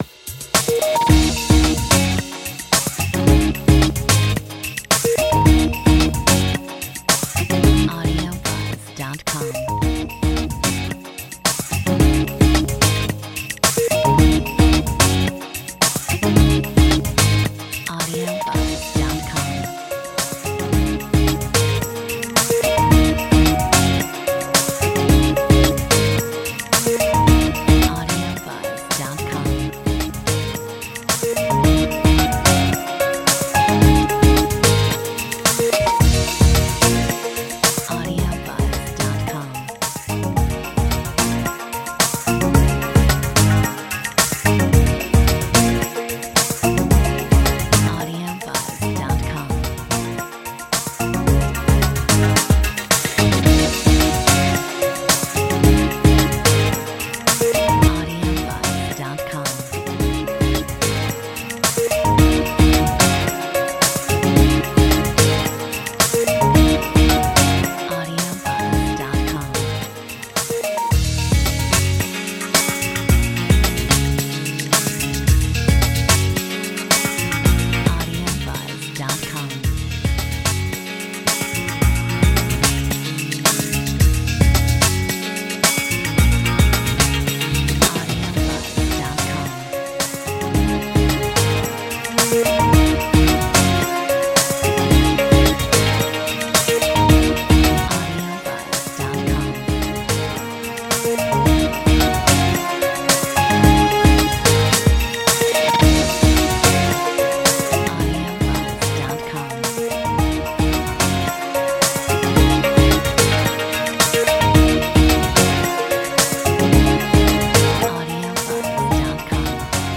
Metronome 110